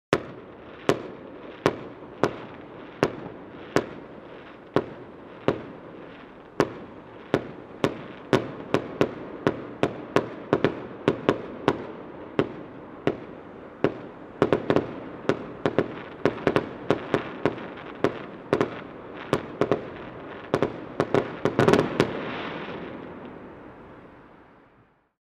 Fireworks-exploding-continuously-sound-effect.mp3